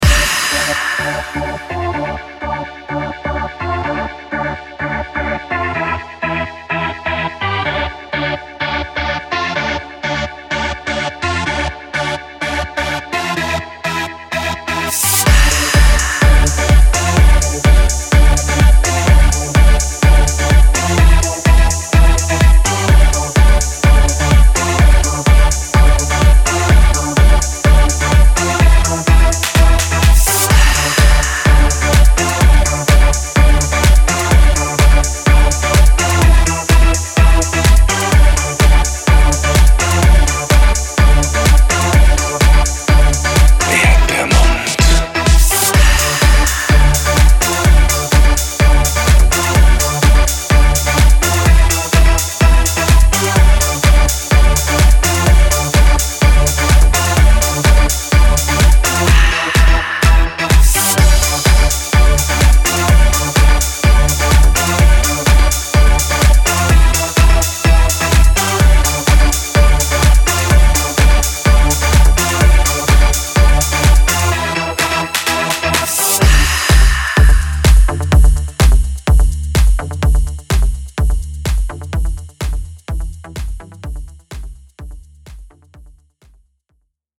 Vocals
Gitarre